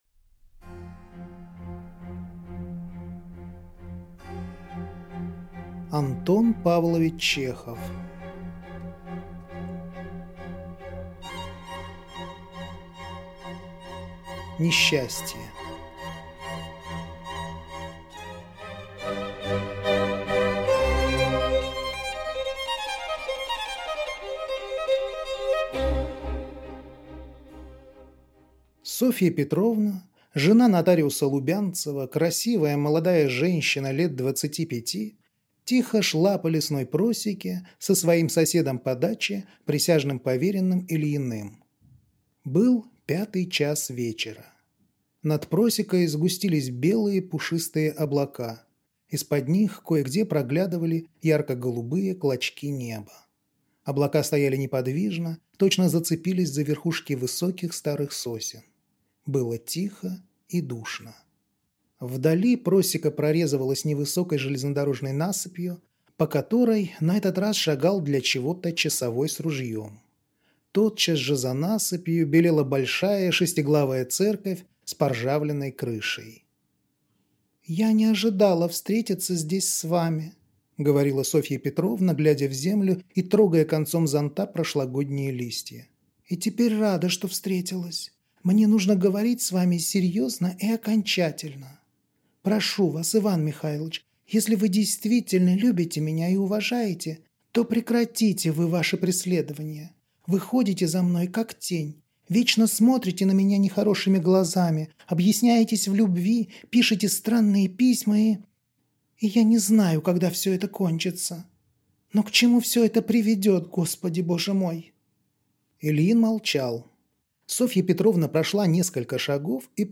Аудиокнига Несчастье | Библиотека аудиокниг